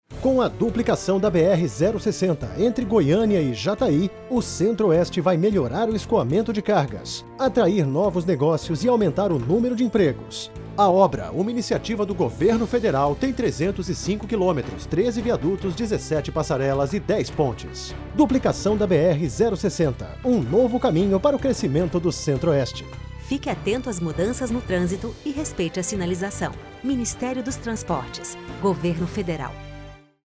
Min._dos_Transportes_-_Spot_-_Duplicação_BR_060_v2.mp3